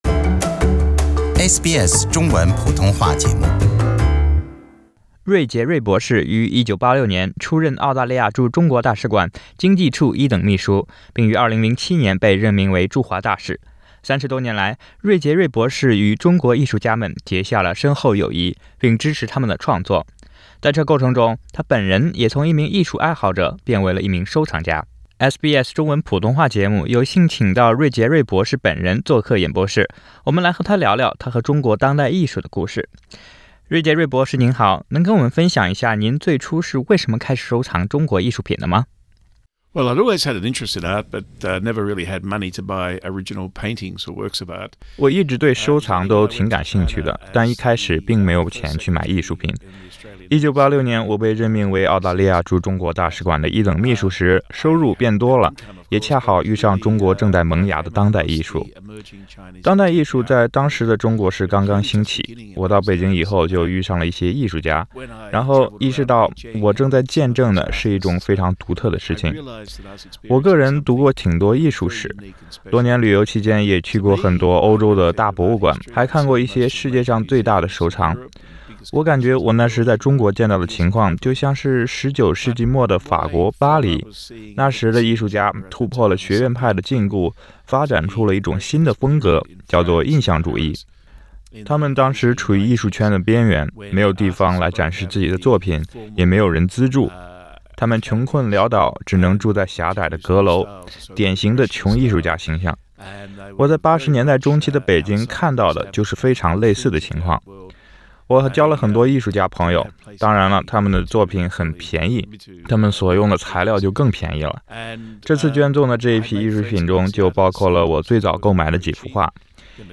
当代艺术见证中国巨变：专访前驻华大使芮捷锐